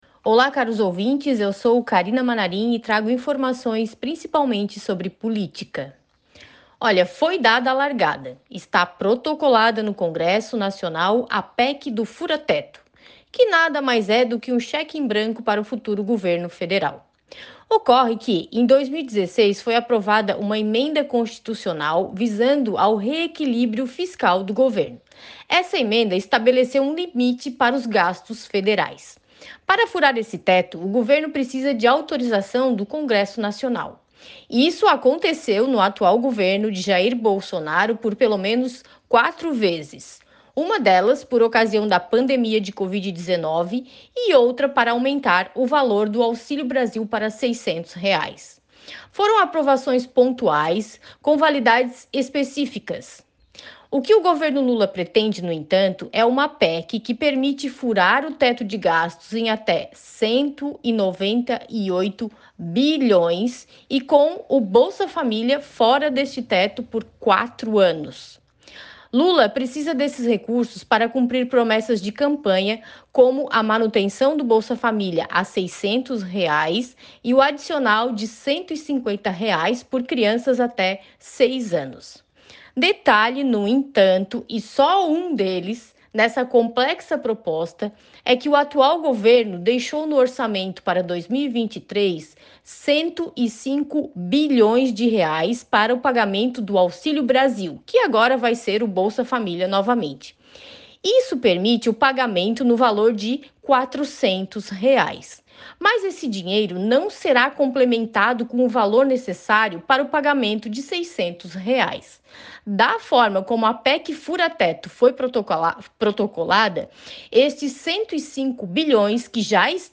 Jornalista ressalta que se PEC for aprovada, o Governo Federal poderá furar o teto de gastos em até R$ 198 bilhões por um período de quatro anos